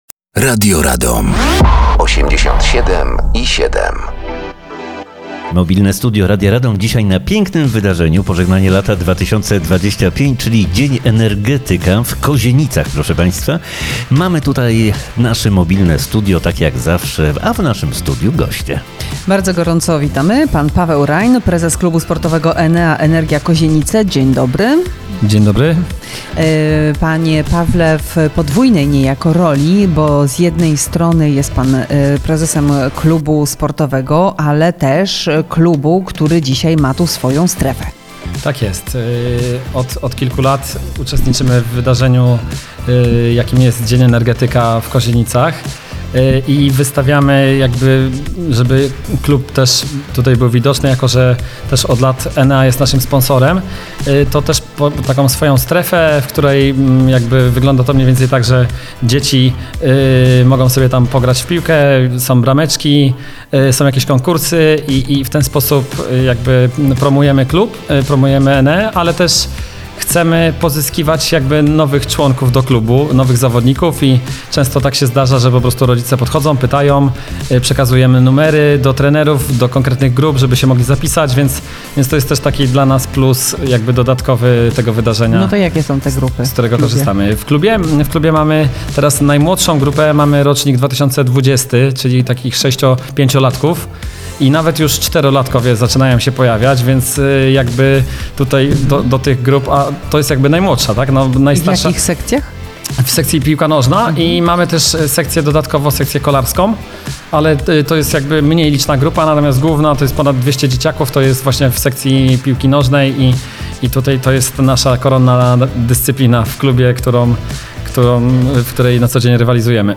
W Mobilnym Studiu Radia Radom